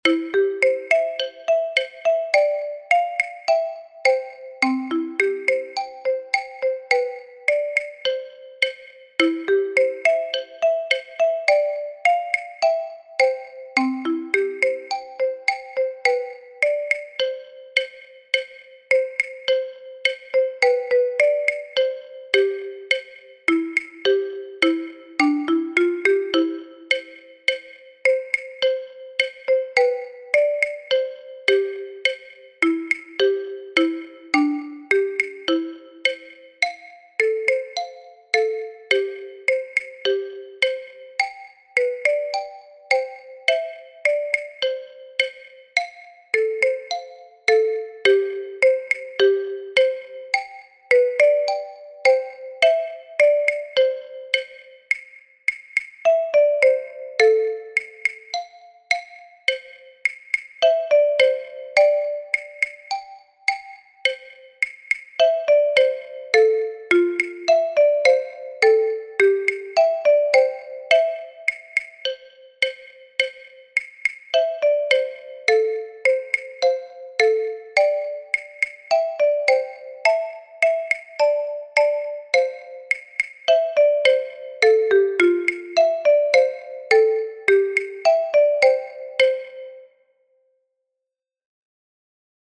Marimba
marimba, xylophone, rhythm stick